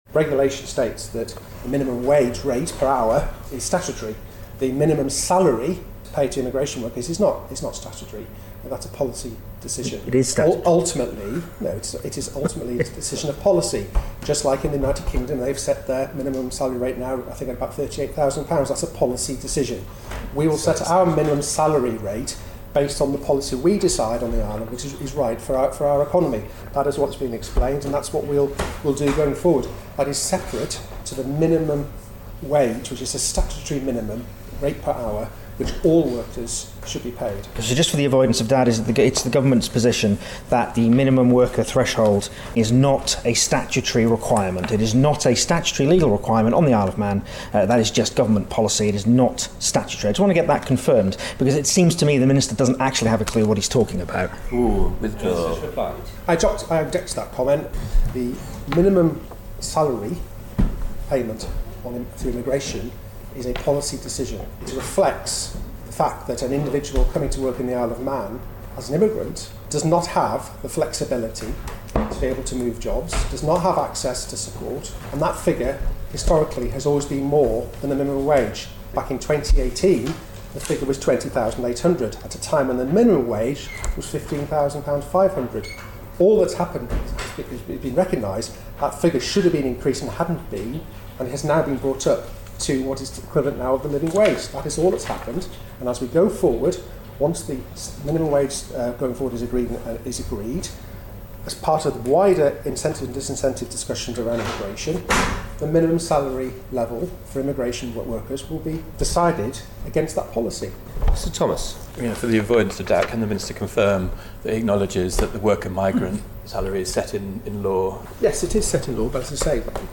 Tim Johnston has been quizzed on the topic in the House of Keys.
But Mr Johnston says the two are in no way linked: